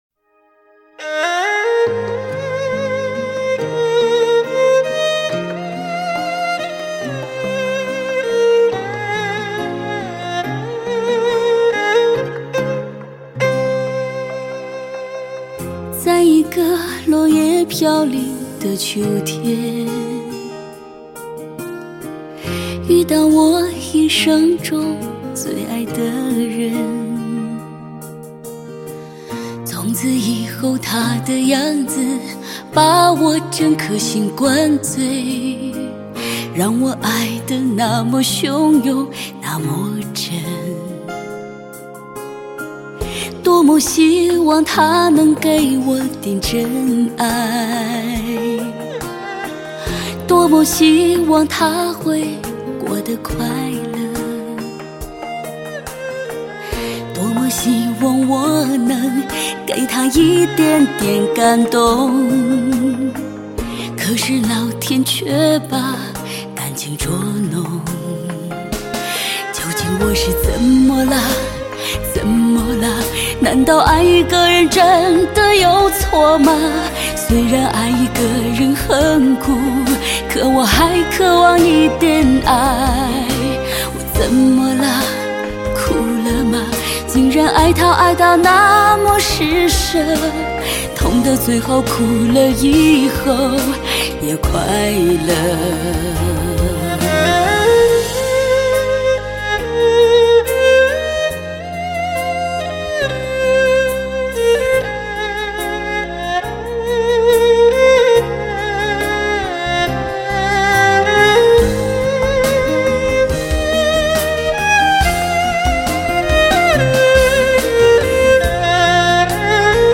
一种若有若无的声音 直达灵魂最深处的疼痛
她成熟的声音透出一种凄凉而曼丽的美。
她妖娆，清晰，飘逸，玲珑，洒脱，诡异，纤细，端丽...